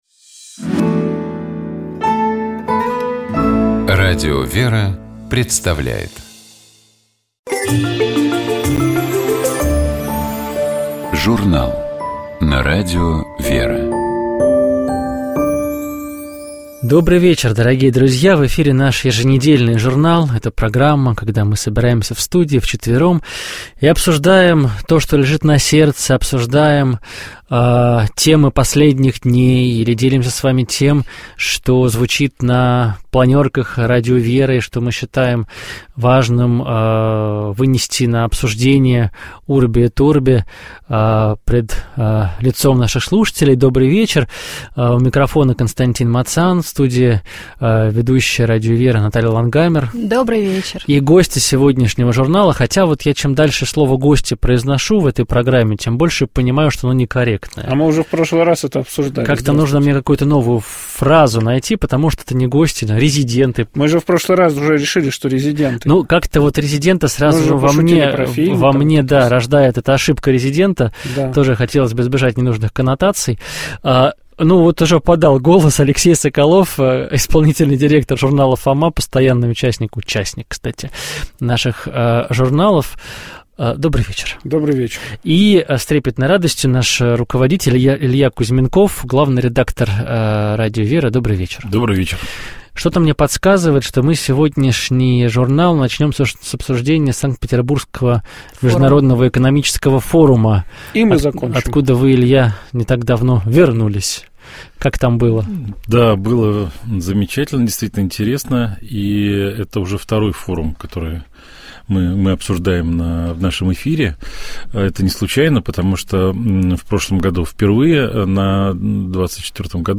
В программе прозвучат записи рождественских бесед отца Александра Шмемана на радио "Свобода".